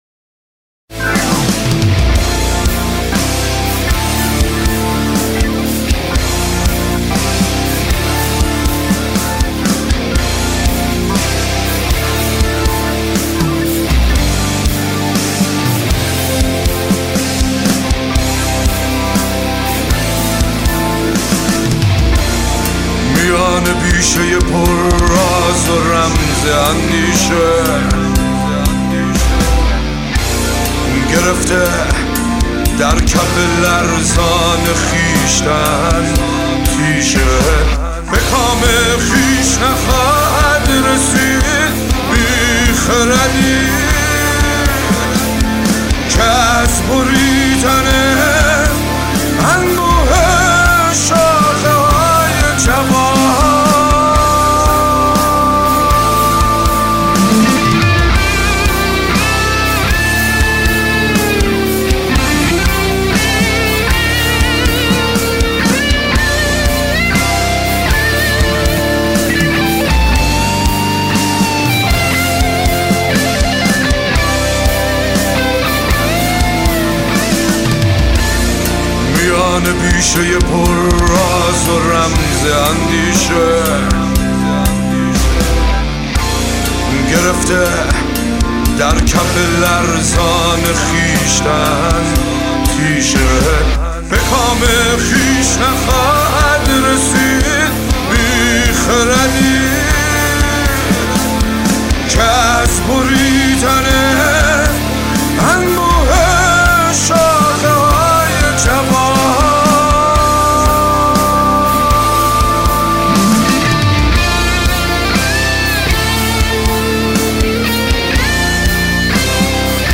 Contemporary